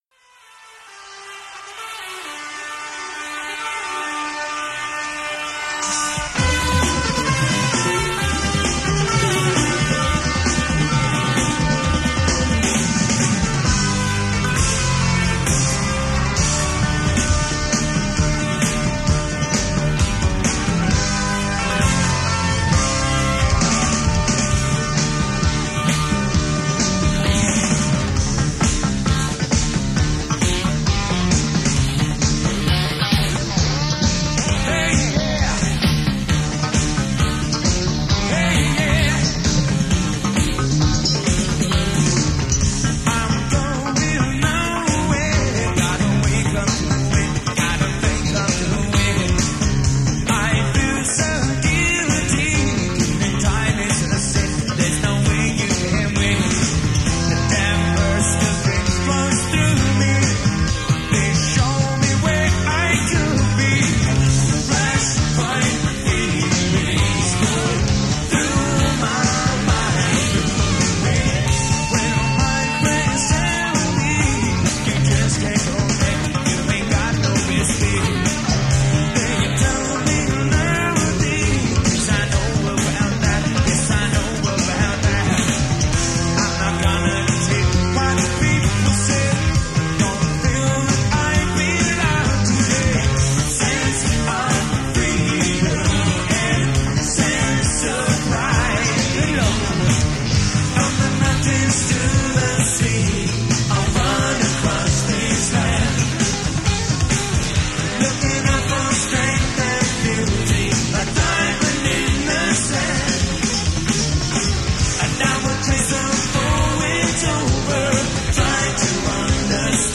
A predilection towards gettin’ Funky.